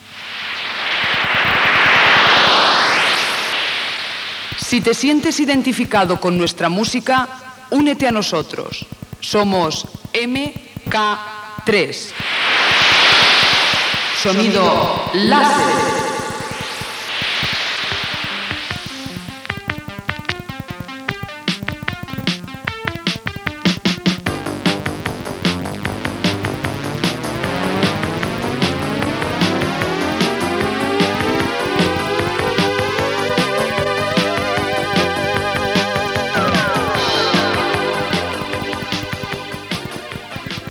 d82f834985d3bf73adbfdff0c1adf8d6f7fedada.mp3 Títol MK3 Emissora MK3 Titularitat Tercer sector Tercer sector Comercial Descripció Indicatiu de l'emissora i música.